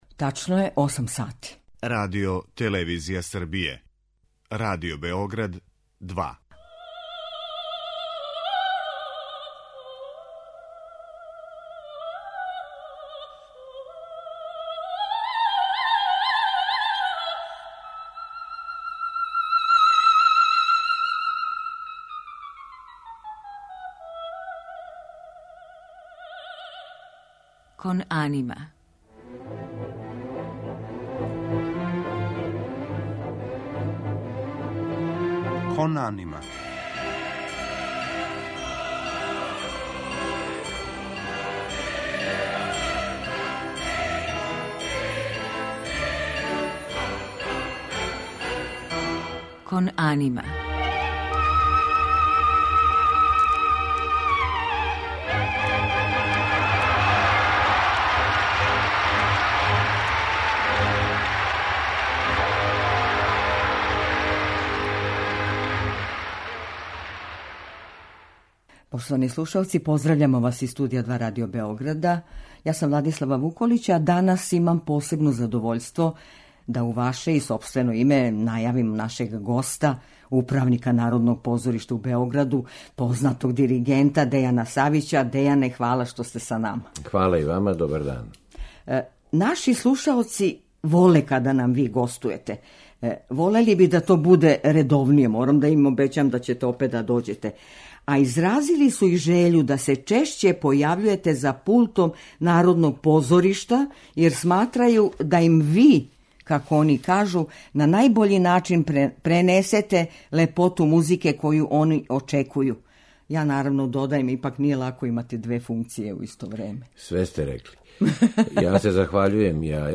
У музичком делу биће емитовани фрагменти из опера у извођењу наших најпознатијих вокалних уметника.